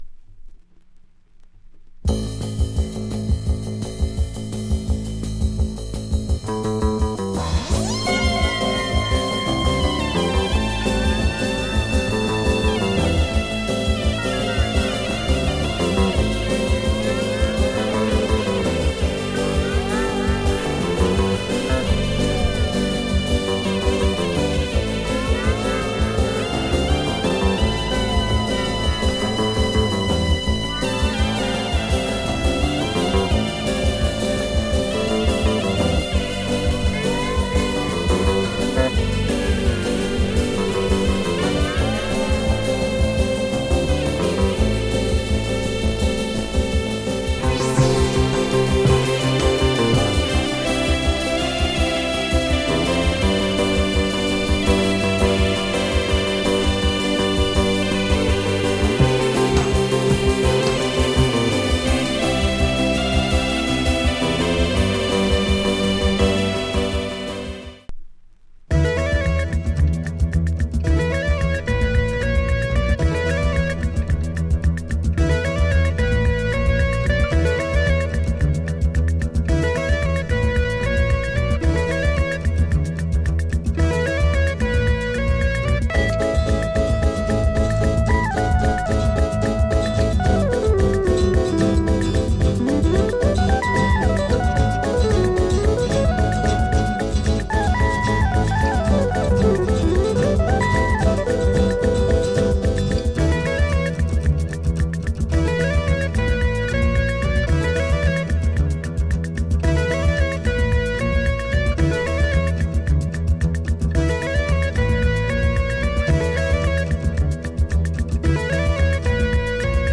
HomeFrench / Euro Pop  >  European Grooves
Classic french library LP
dope cosmic sounds